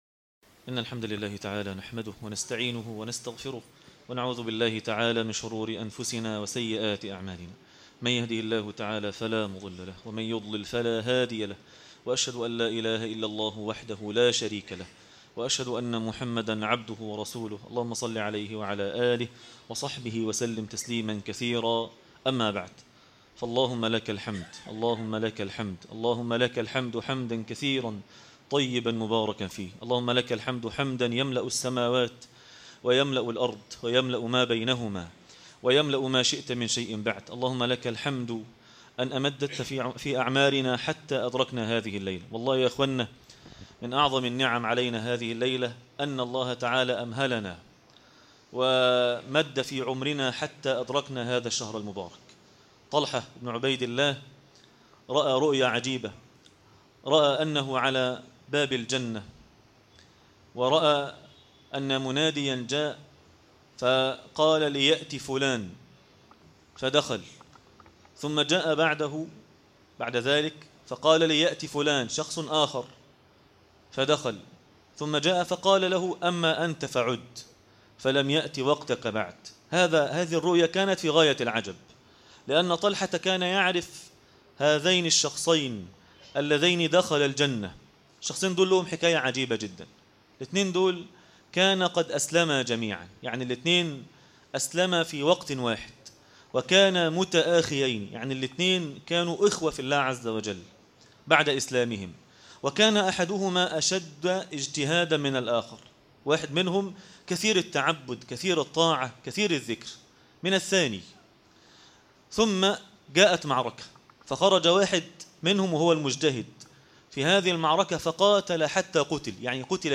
يا باغي الخير أقبل - درس التراويح ليلة 1 رمضان 1438هـ